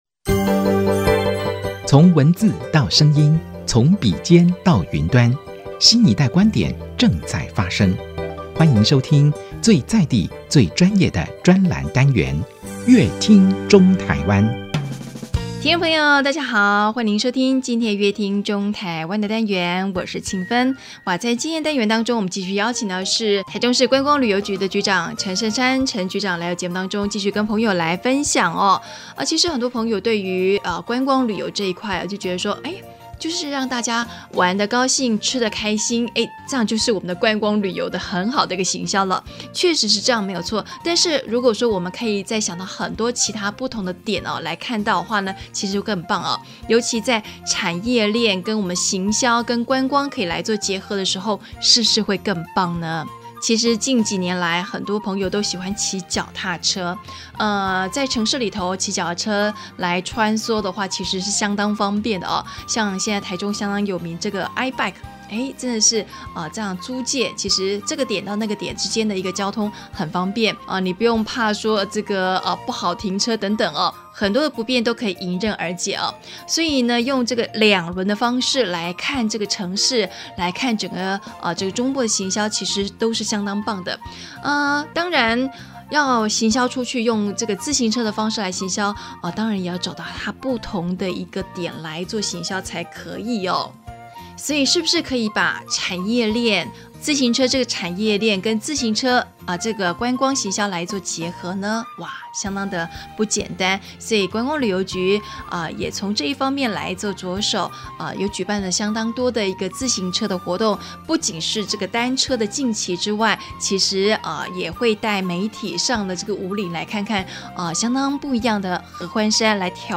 本集來賓：臺中市政府觀光旅遊局陳盛山局長 本集主題：用雙腳踩踏輪轉觀光奇蹟 本集內容： 有山有海的台中，要如何